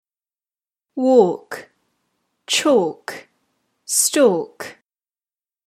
Walk, chalk, and stalk are all pronounced without the /l/.
walk-chalk-stalk.mp3